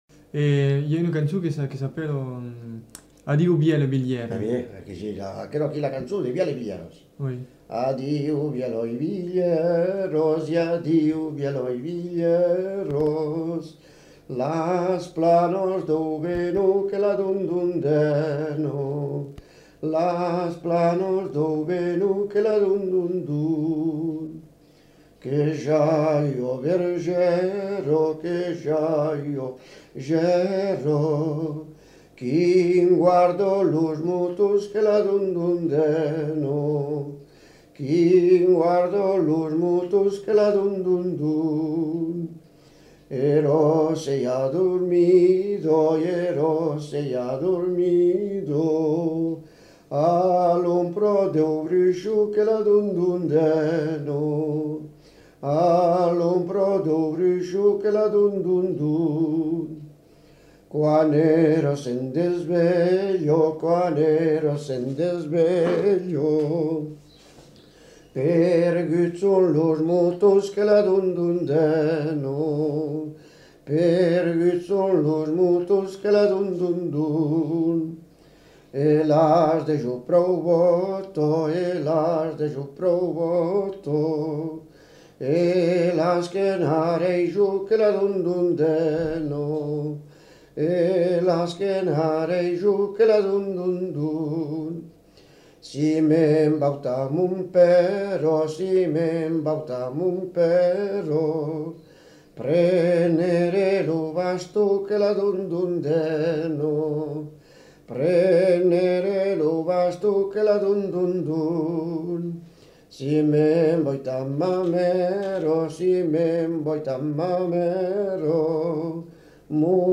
Aire culturelle : Béarn
Lieu : Bielle
Genre : chant
Effectif : 1
Type de voix : voix d'homme
Production du son : chanté
Danse : branlo airejan